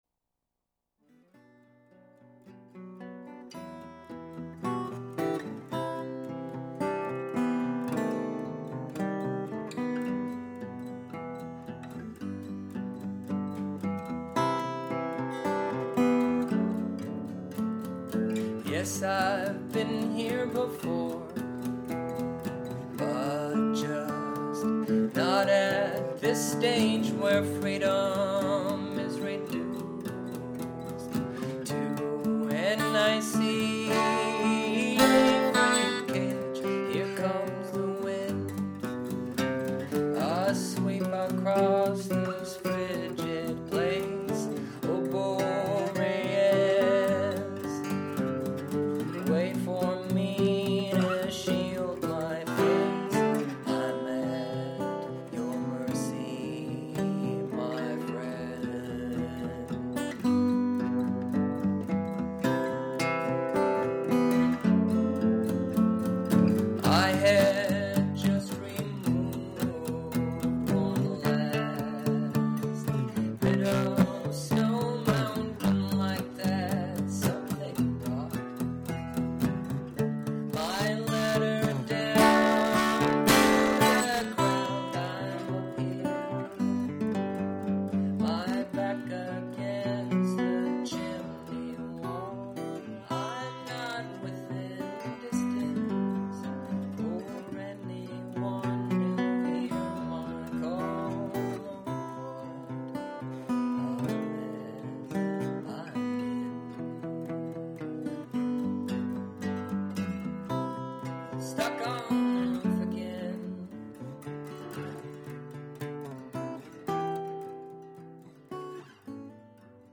This is a live performance
Folk